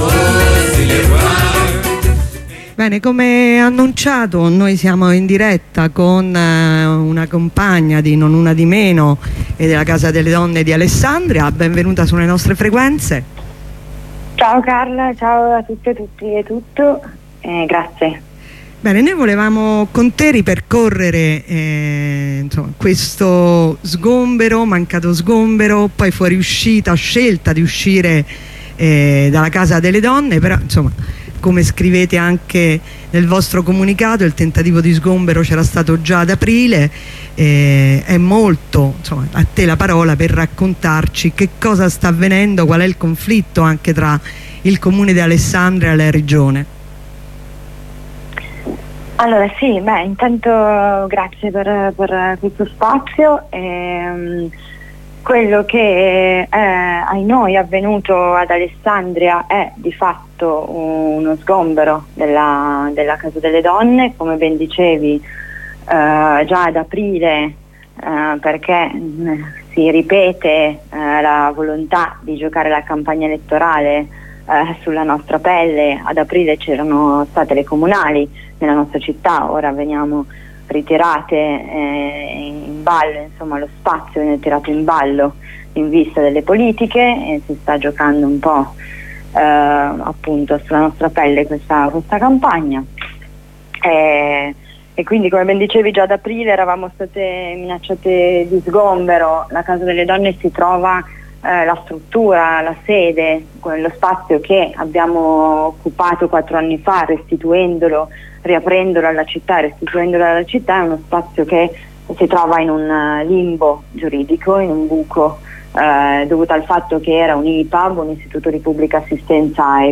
Il commento di un agricoltore